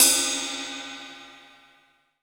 Ride_(Prog_Stepz)_(JW2).wav